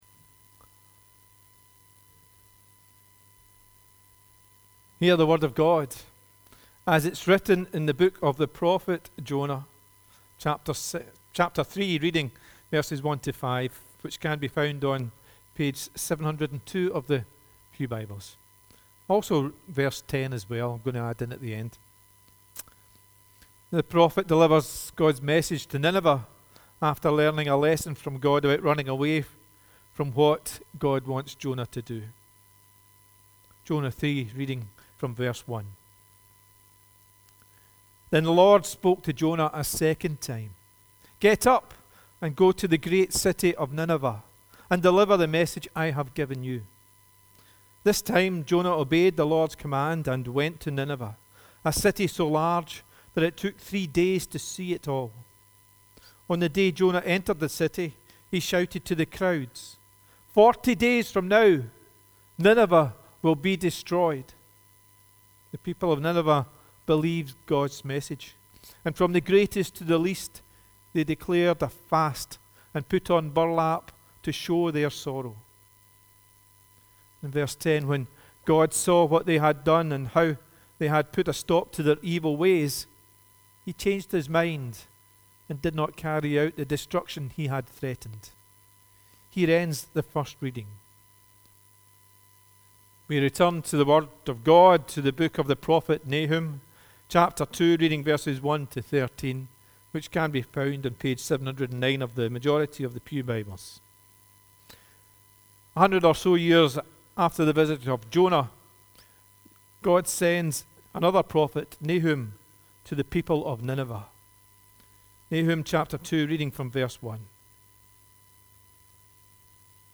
The readings prior to the sermon are Jonah 3: 1-5, 10 and Nahum 2: 1-13